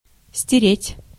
Ääntäminen
Ääntäminen US : IPA : [ˈwɑɪp]